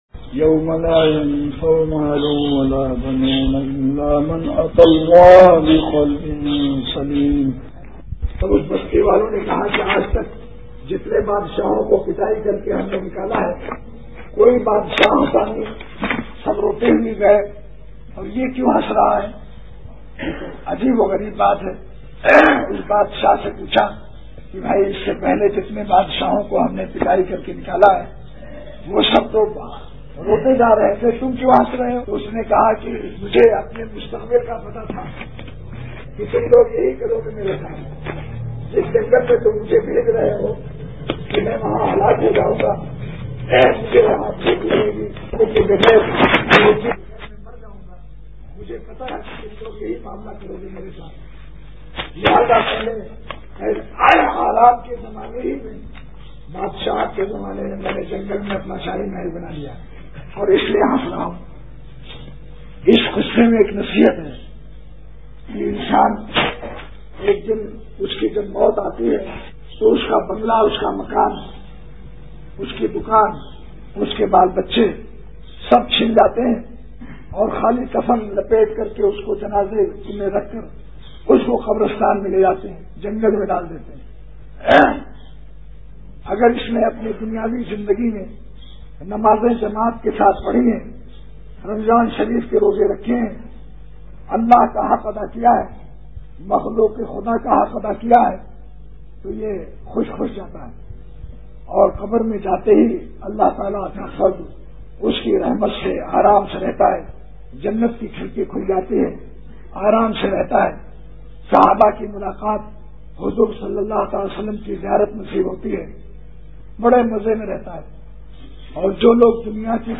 Delivered at Ilaahabad, India.